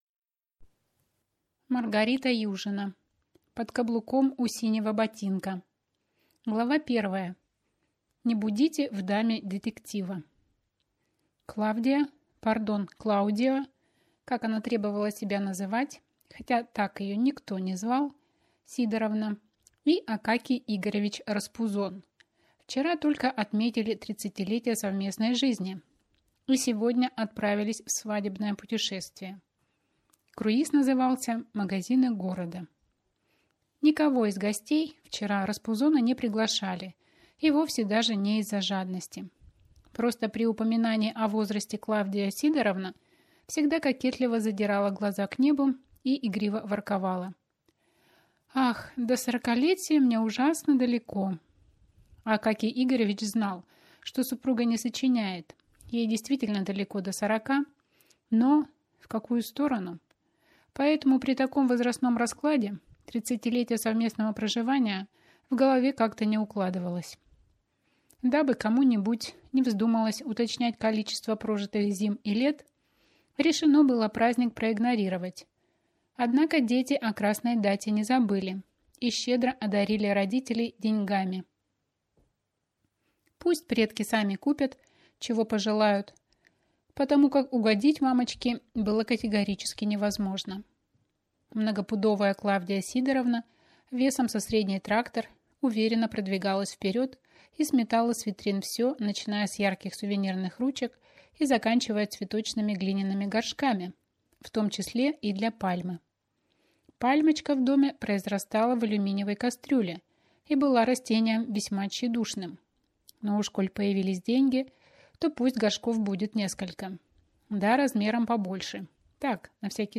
Аудиокнига Под каблуком у синего ботинка | Библиотека аудиокниг